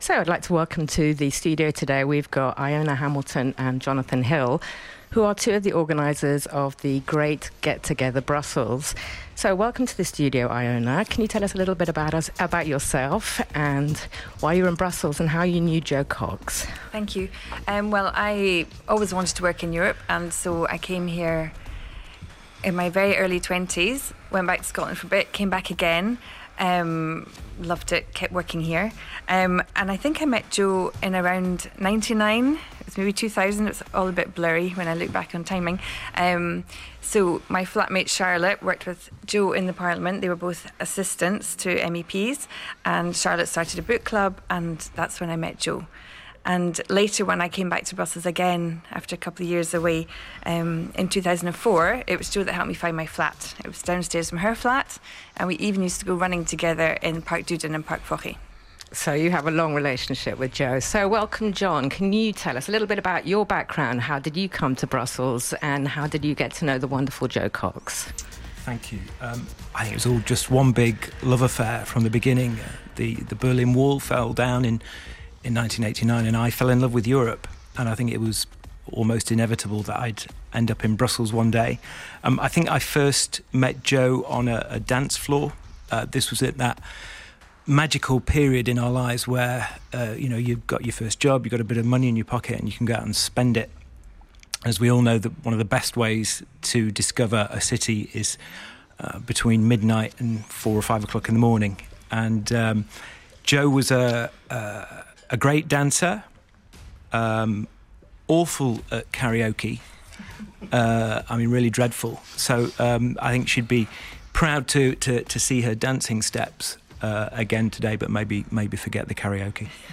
our guests in the studio